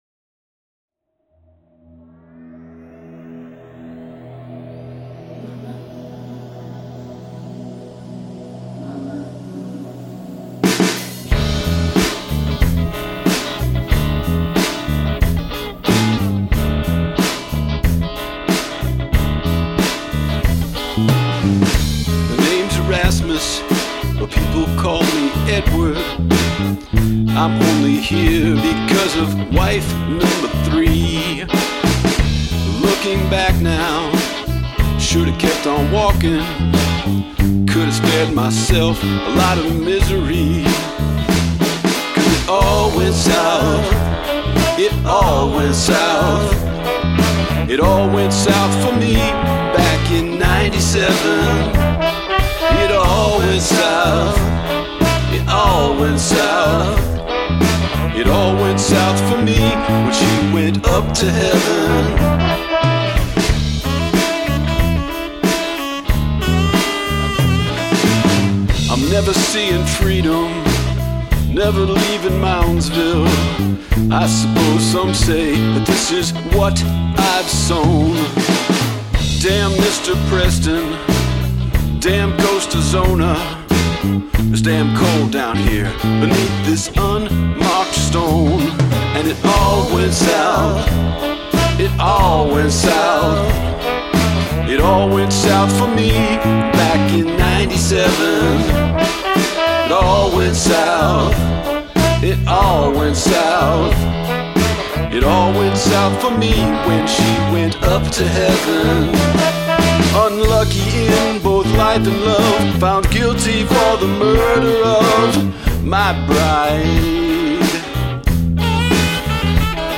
alto and tenor saxomaphones